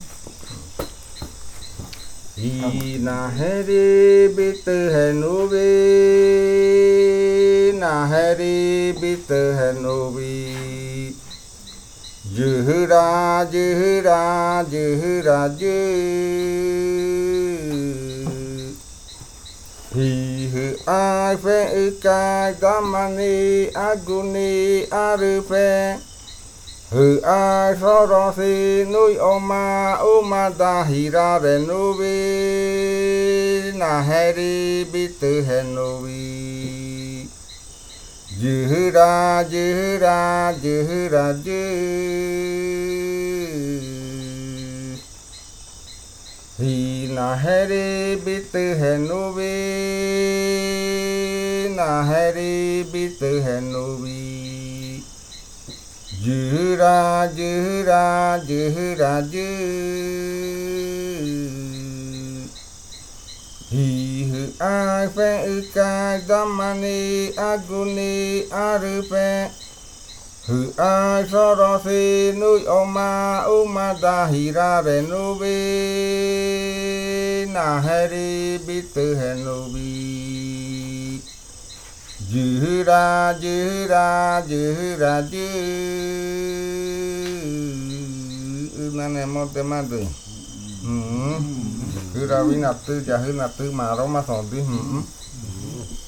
Leticia, Amazonas, (Colombia)
Grupo de danza Kaɨ Komuiya Uai
Canto fakariya de la variante Jimokɨ (cantos de guerrero).
Fakariya chant of the Jimokɨ variant (Warrior chants).